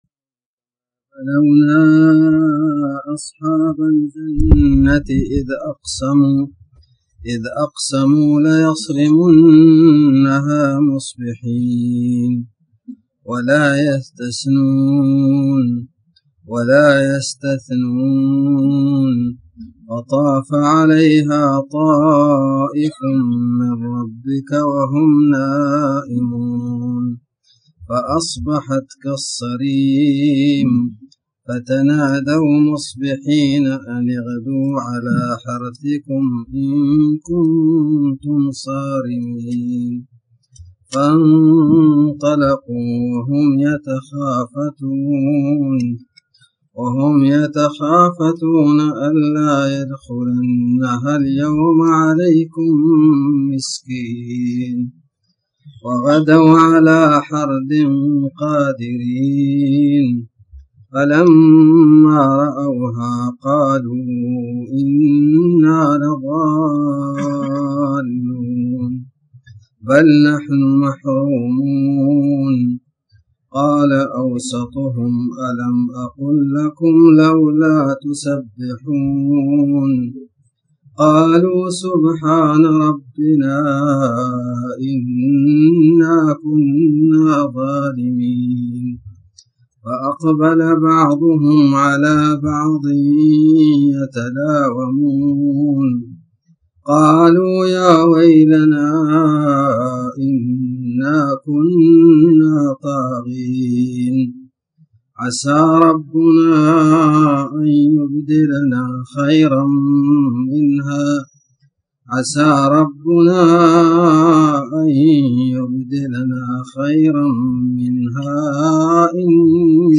تاريخ النشر ٢٣ ربيع الأول ١٤٣٩ هـ المكان: المسجد الحرام الشيخ